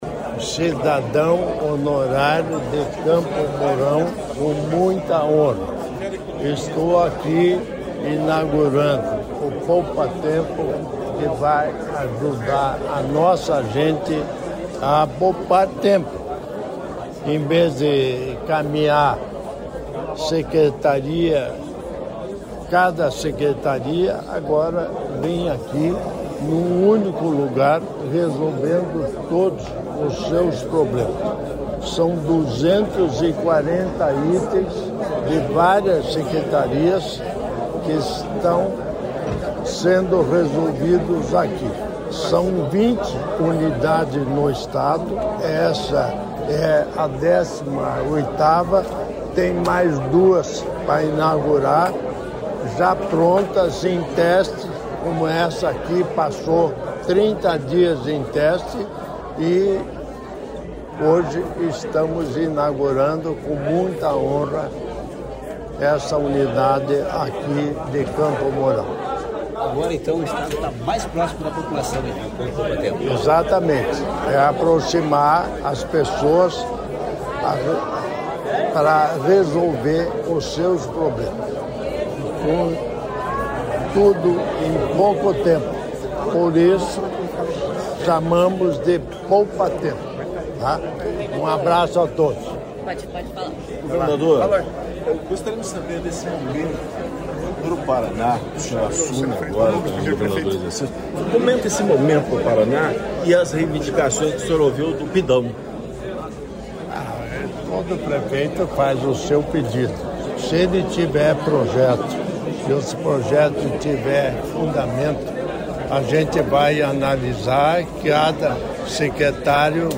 Sonora do governador em exercício Darci Piana sobre a inauguração do Poupatempo em Campo Mourão